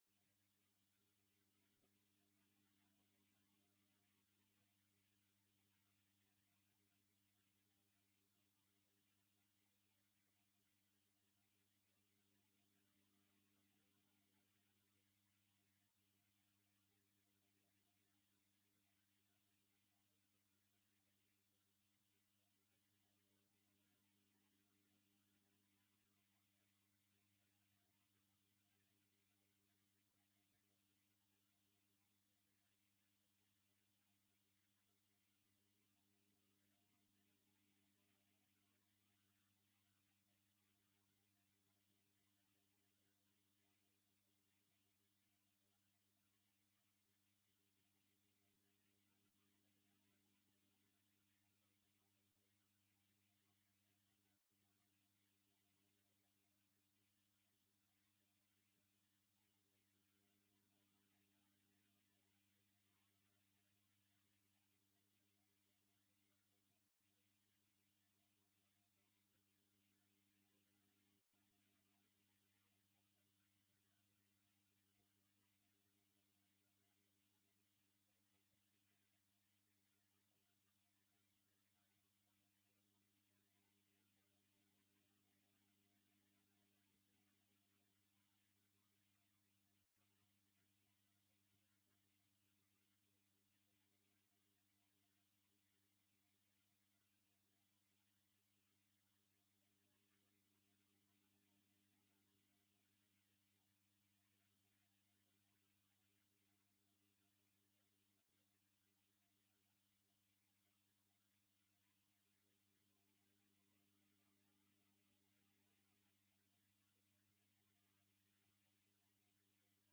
Město Mariánské Lázně: 10. jednání zastupitelstva města Mariánské Lázně (13.10.2015) f8f706f77fbd667659a235196eeff0c0 audio